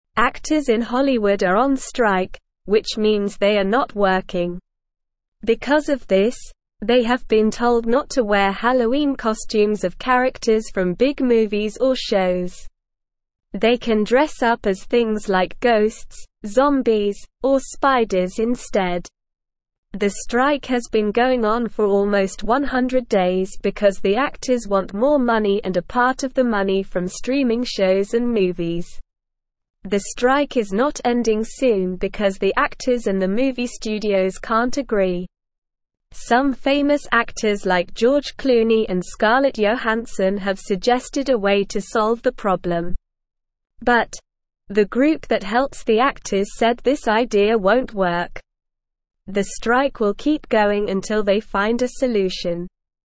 Slow
English-Newsroom-Beginner-SLOW-Reading-Hollywood-Actors-on-Strike-No-Movie-Costumes-Allowed.mp3